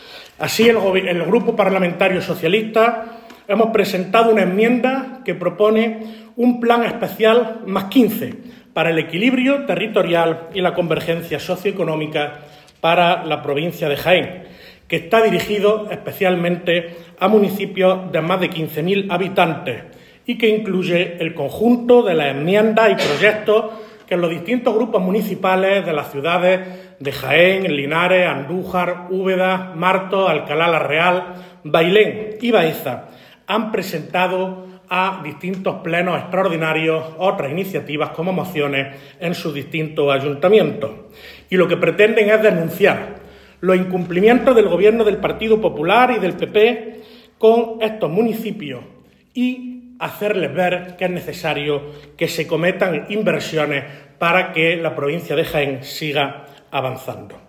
Cortes de sonido